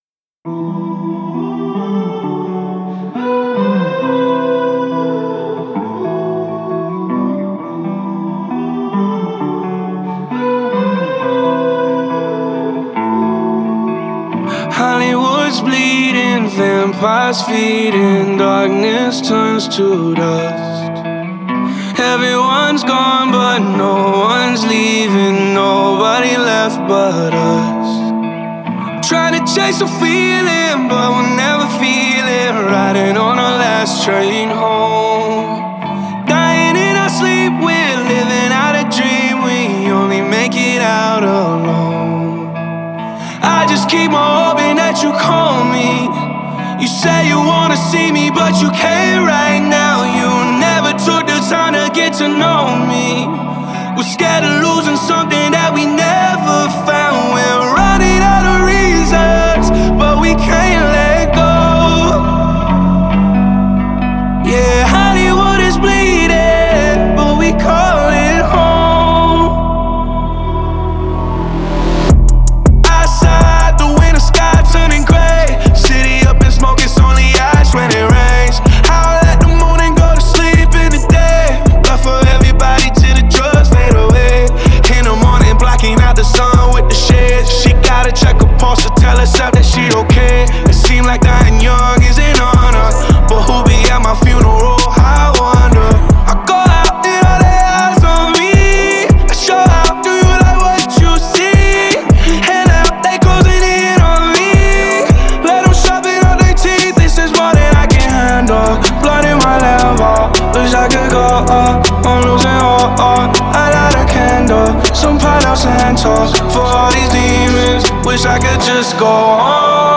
Hip-Hop, Pop, R&B, Trap, Alternative Rock, Pop Rap